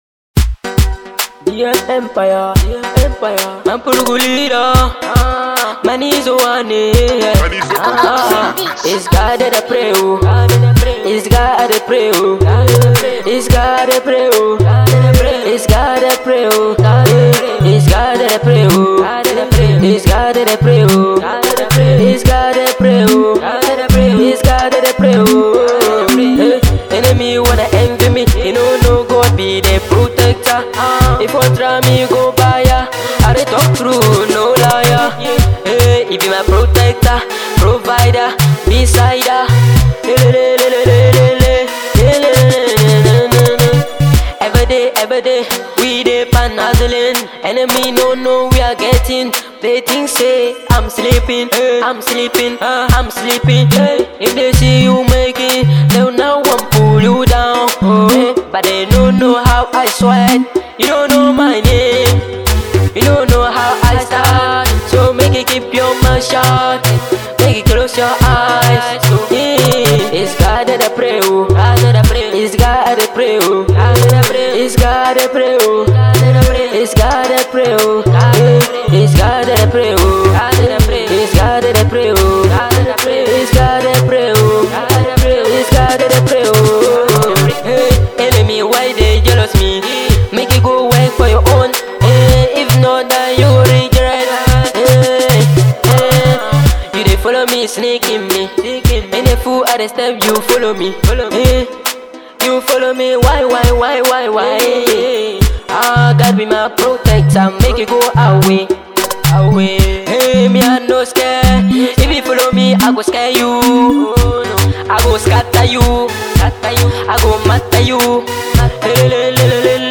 is a powerful highlife song